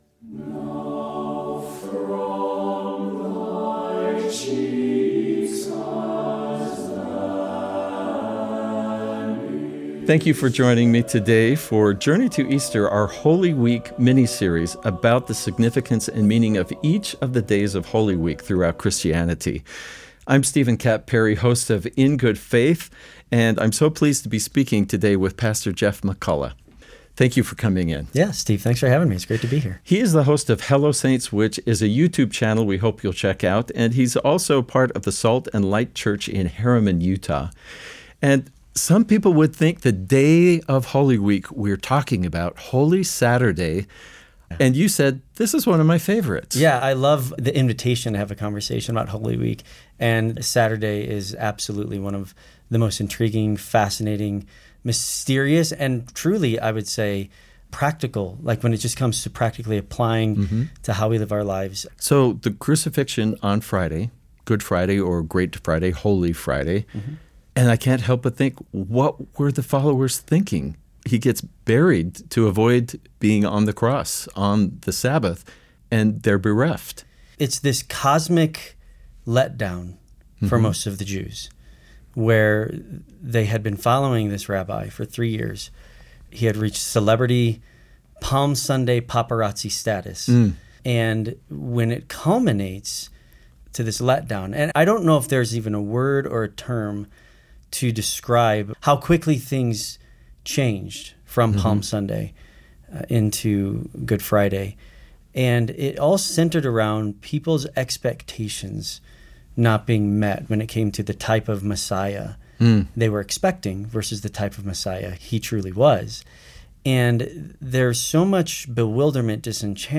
Sermons and homilies